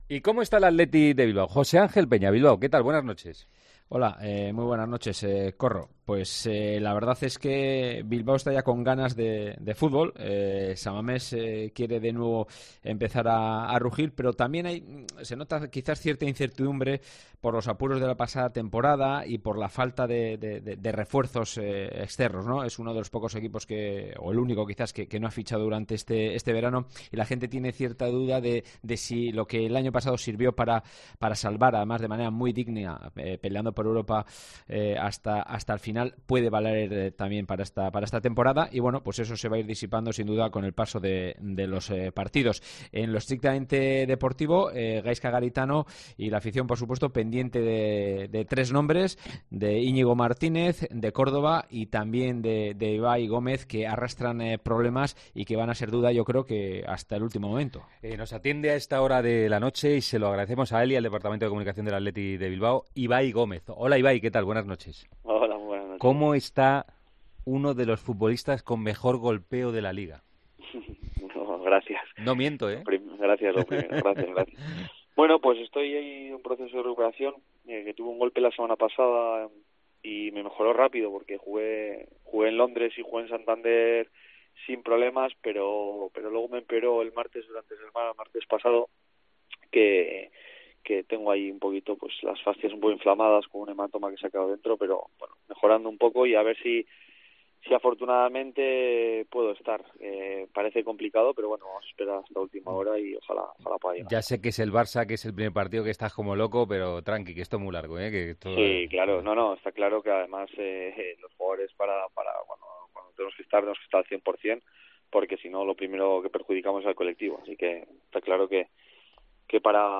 Ibai Gómez , jugador del Athletic Club , pasó este miércoles por los micrófonos de 'El Partidazo de COPE ' para analizar el arranque de LaLiga y el estreno de los bilbaínos este viernes ante el Barça.